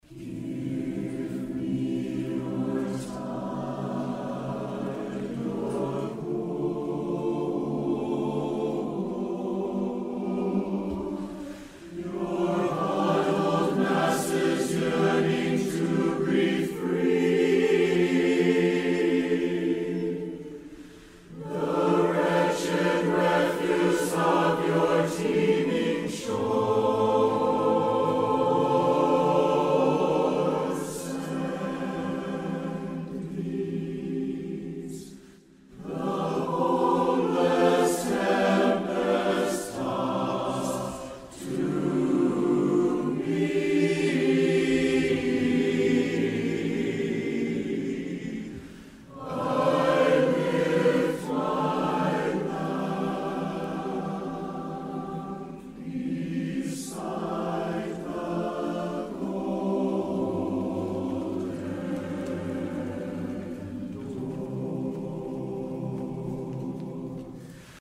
qui és el rapsode  que recita l’Automme de Lamartine al segon fragment sonor.